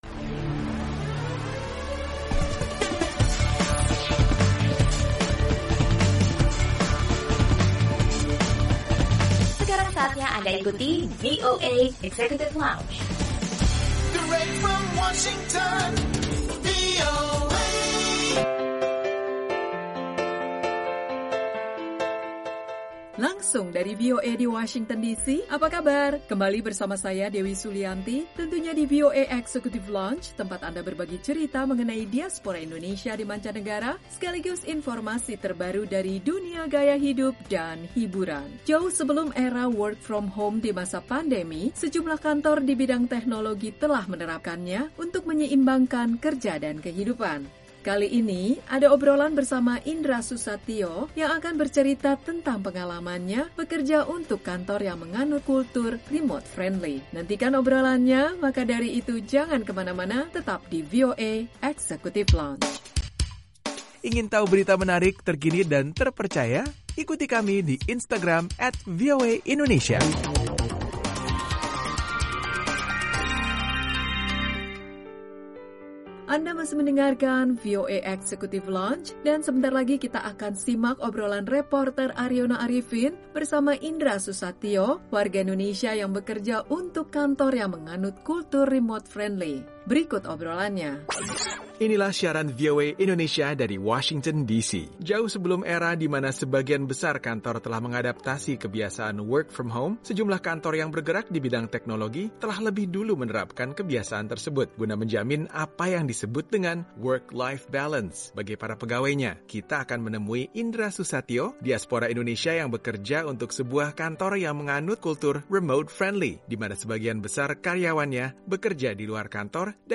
Obrolan reporter